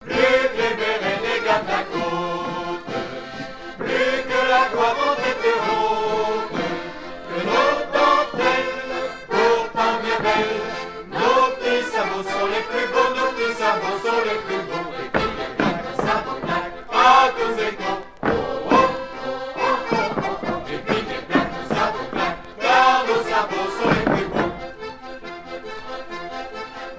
gestuel : danse
Groupe folklorique des Sables-d'Olonne
Pièce musicale éditée